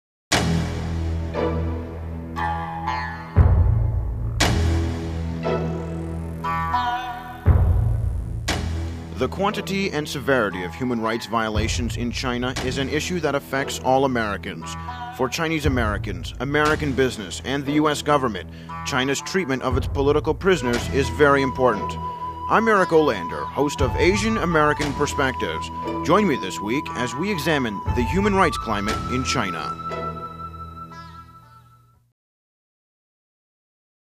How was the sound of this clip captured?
Each show was accompanied by a 30 second promotional spot that NPR affiliates could run during the week to invite listeners to tune in to Asian American Perspectives.